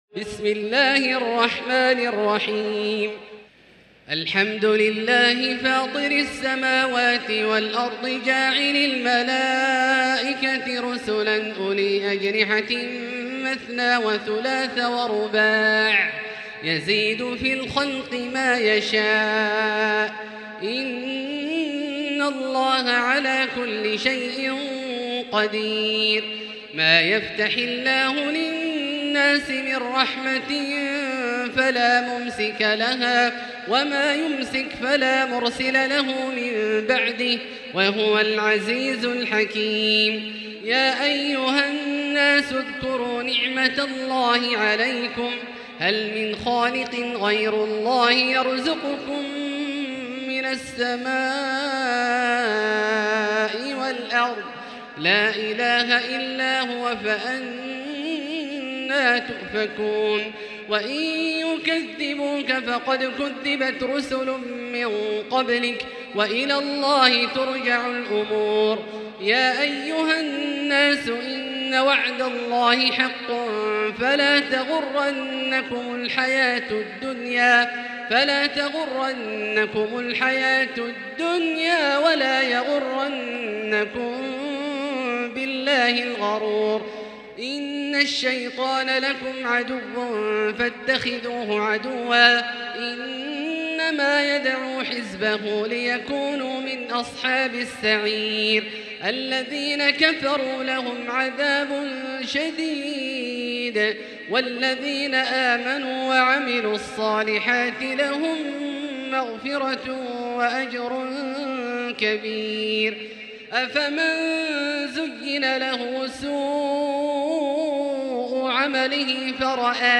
المكان: المسجد الحرام الشيخ: فضيلة الشيخ عبدالله الجهني فضيلة الشيخ عبدالله الجهني فاطر